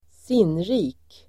Folkets service: sinnrik sinnrik adjektiv, ingenious Uttal: [²s'in:ri:k] Böjningar: sinnrikt, sinnrika Synonymer: genial, utstuderad Definition: fyndig och skarpsinnig Exempel: en sinnrik uppfinning (an ingenious invention)
sinnrik.mp3